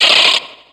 Fichier:Cri 0353 XY.ogg — Poképédia
Cri de Polichombr dans Pokémon X et Y.